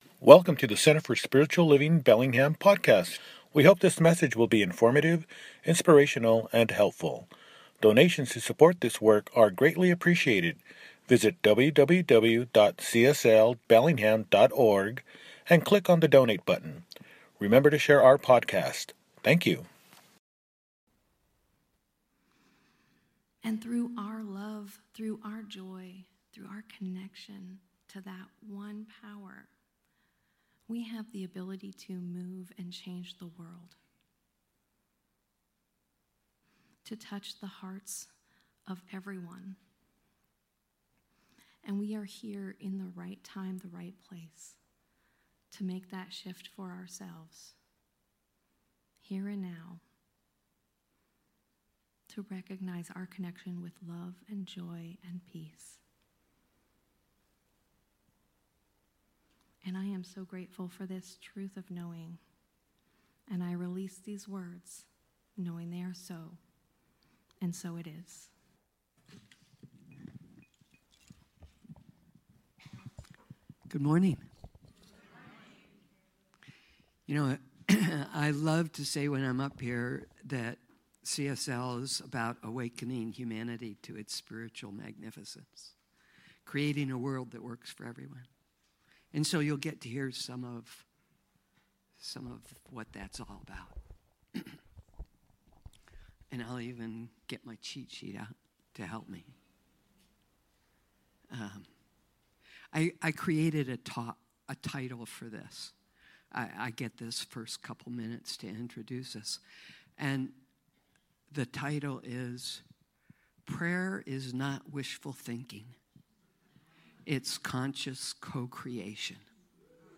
Panel of Practitioners at Your Service! – Celebration Service | Center for Spiritual Living Bellingham
Panel-of-Practitioners-at-Your-Service.mp3